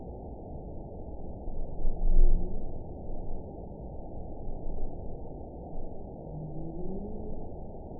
event 910400 date 01/21/22 time 11:41:51 GMT (3 years, 3 months ago) score 5.62 location TSS-AB07 detected by nrw target species NRW annotations +NRW Spectrogram: Frequency (kHz) vs. Time (s) audio not available .wav